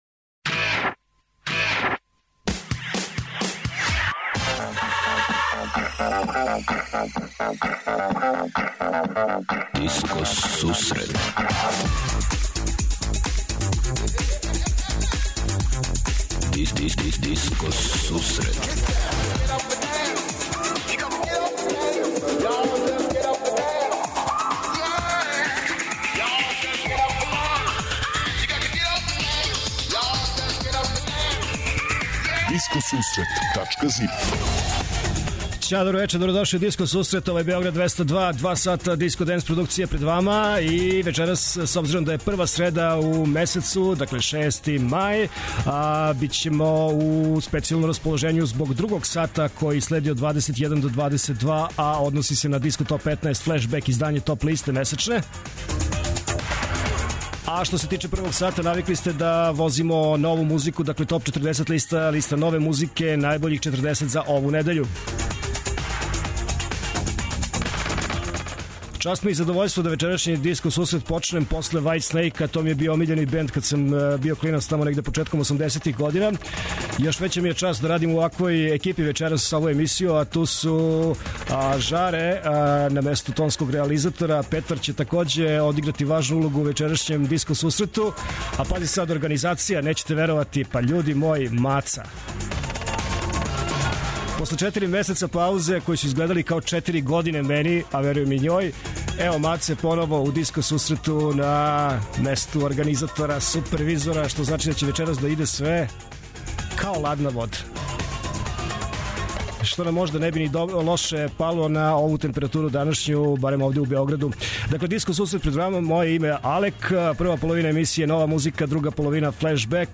Диско сусрет је емисија посвећена најновијој и оригиналној диско музици у широком смислу, укључујући све стилске утицаје других музичких праваца - фанк, соул, РнБ, итало-диско, денс, поп. Непосредан контакт са слушаоцима уз пуно позитивне енергије је основа на којој ова емисија гради забаву сваке среде.